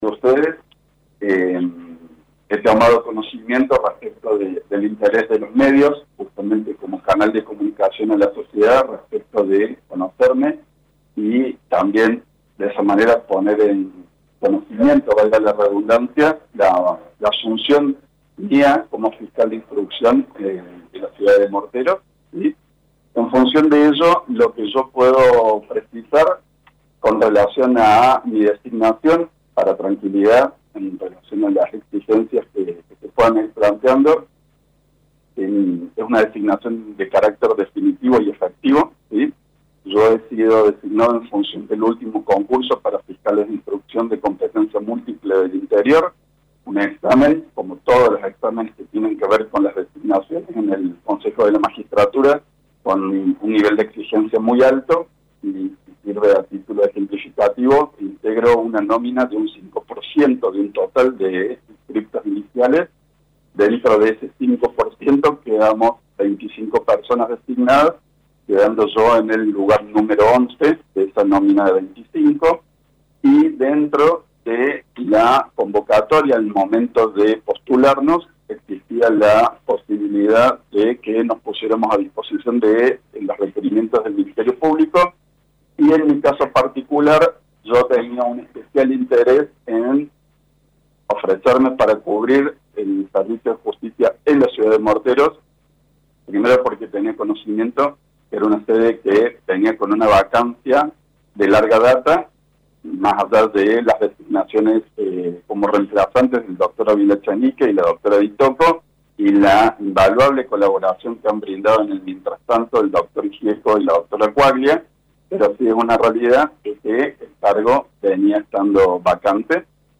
El fiscal de Instrucción con Competencia Múltiple en la Quinta Circunscripción Judicial, Francisco Payges, ofreció este viernes una conferencia de prensa en la ciudad de Morteros, en lo que fue su presentación oficial ante los medios locales.